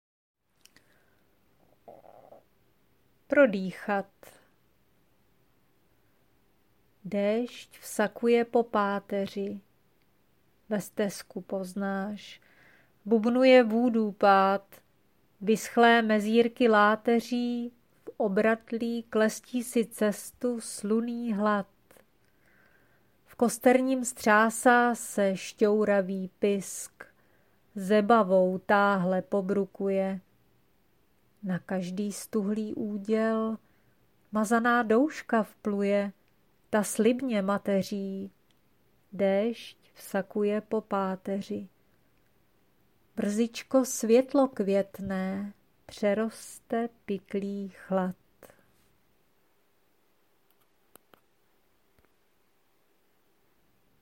Miniatura, hříčka » Ze života